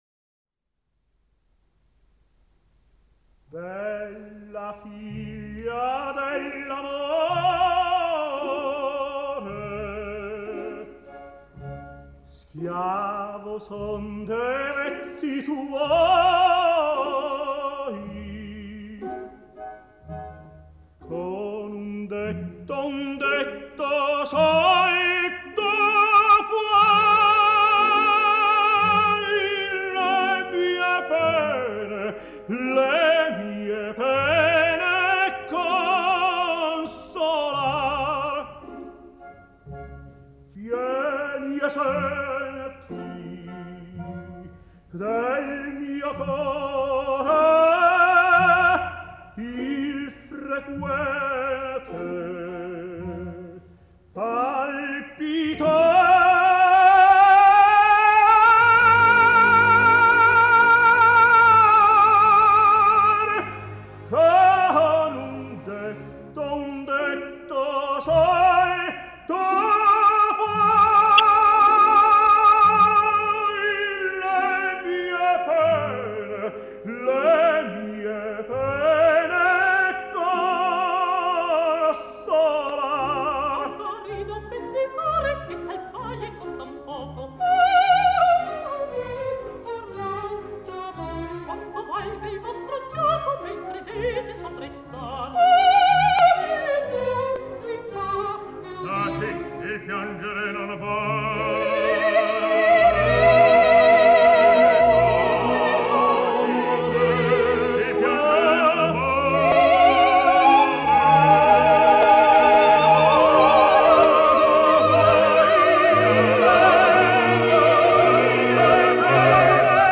quartetto del Rigoletto Ascolta il quartetto del Rigoletto (Bella figlia dell’amore, appunto) che era un loro cavallo di battaglia.